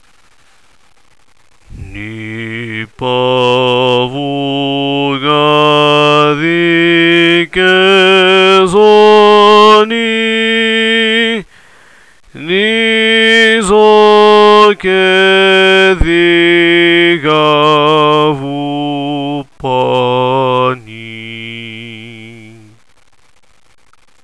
00- La escala
00-escala.wav